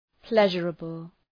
Προφορά
{‘pleʒərəbəl}